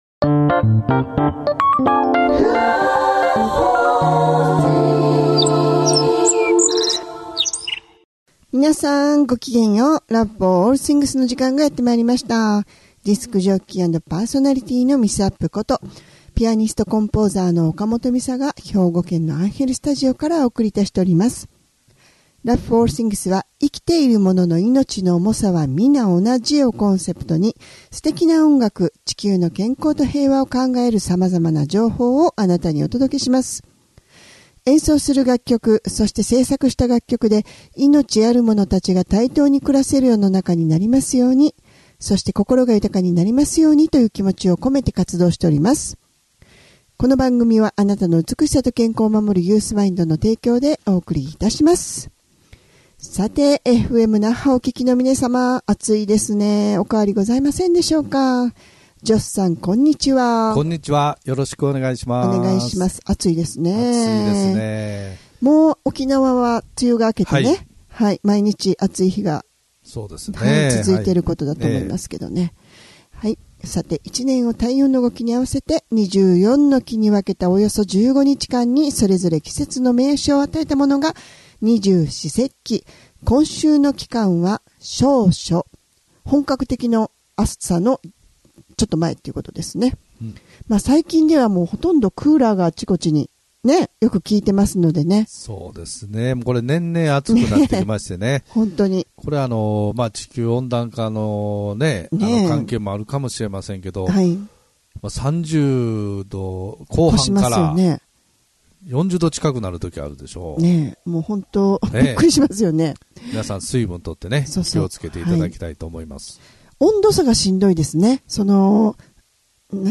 生きているものの命の重さは同じというコンセプトで音楽とおしゃべりでお送りする番組です♪